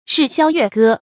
日削月割 注音： ㄖㄧˋ ㄒㄩㄝ ㄩㄝˋ ㄍㄜ 讀音讀法： 意思解釋： 每日每月割讓土地。